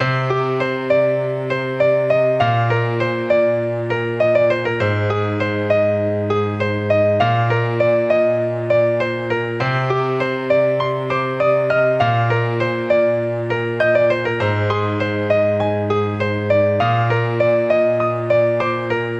Tag: 100 bpm RnB Loops Piano Loops 3.23 MB wav Key : Unknown